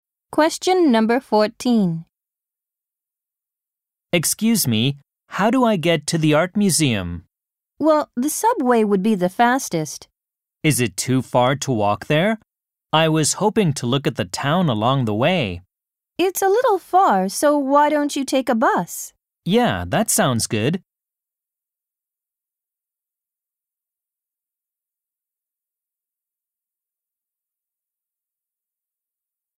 ○共通テストの出題音声の大半を占める米英の話者の発話に慣れることを第一と考え，音声はアメリカ（北米）英語とイギリス英語で収録。
第3問　問14 （アメリカ（北米）英語）